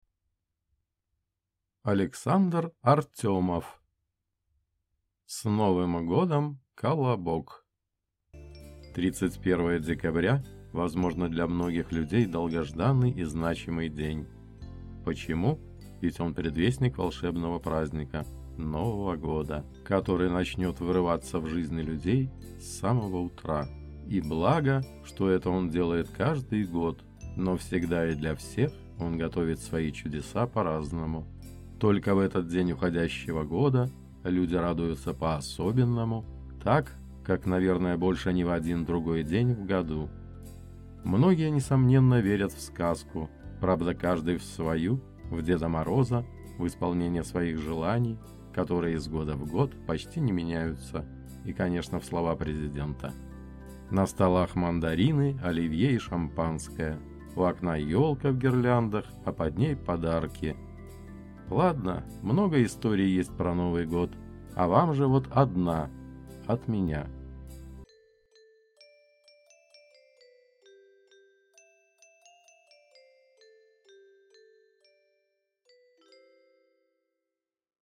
Аудиокнига С Новым Годом, «Колобок» | Библиотека аудиокниг